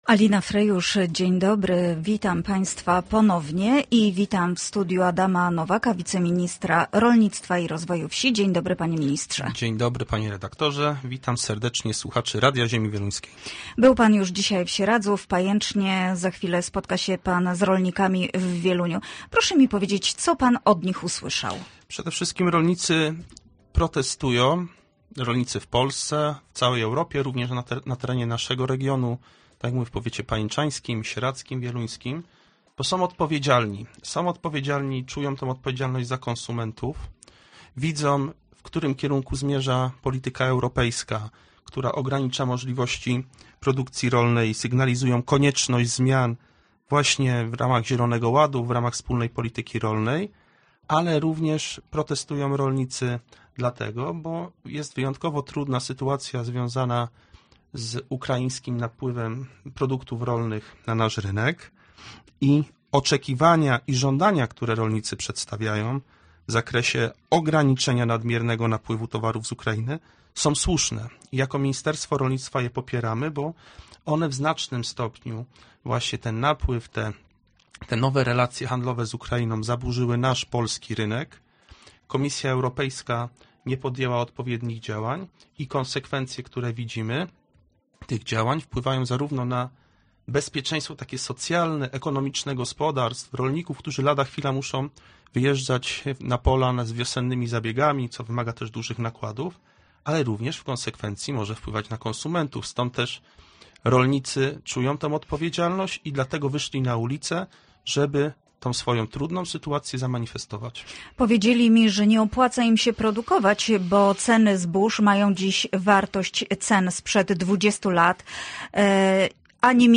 Gościem Radia ZW był Adam Nowak, podsekretarz stanu w Ministerstwie Rolnictwa i Rozwoju Wsi